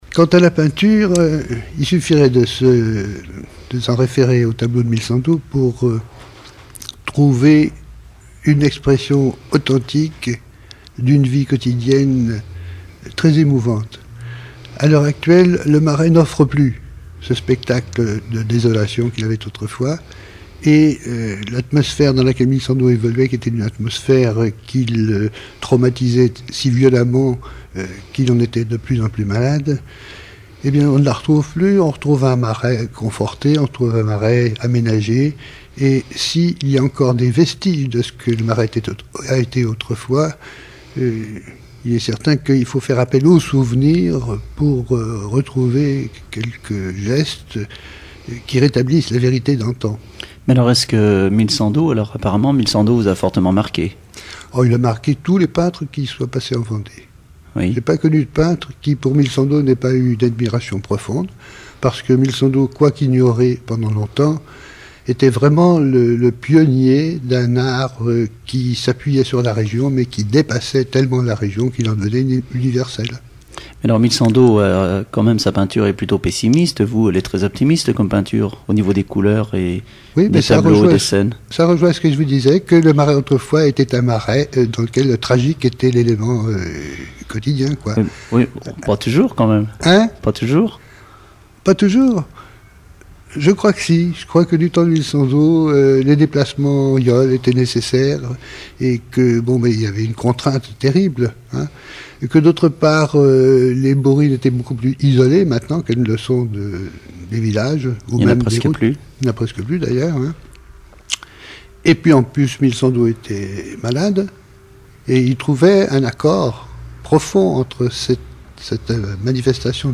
Mémoires et Patrimoines vivants - RaddO est une base de données d'archives iconographiques et sonores.
Enquête Alouette FM numérisation d'émissions par EthnoDoc
Catégorie Témoignage